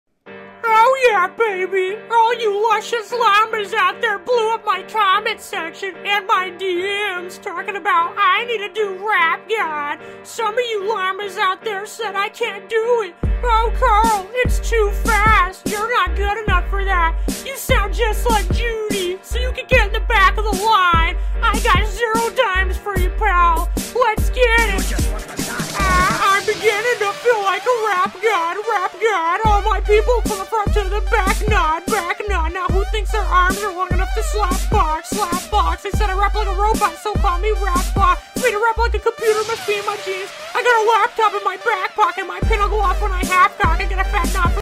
Carl Wheezer Rap